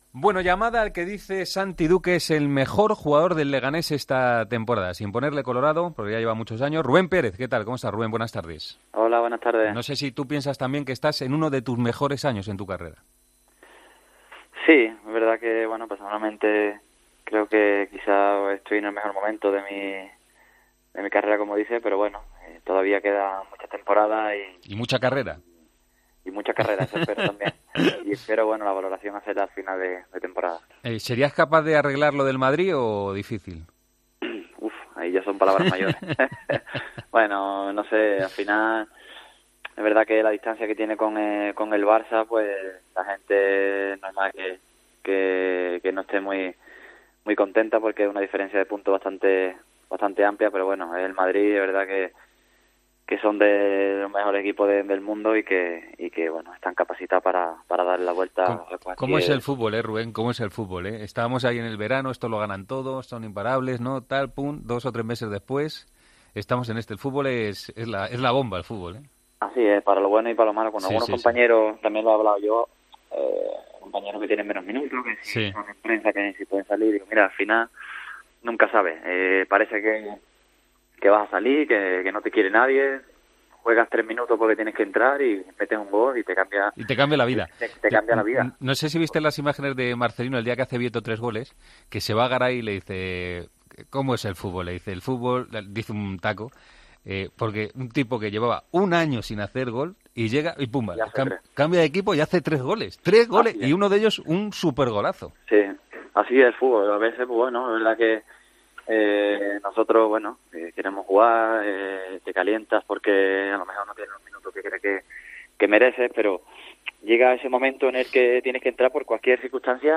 Hablamos con el centrocampista del Leganés antes del partido de Copa con el Real Madrid.